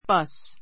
bʌ́s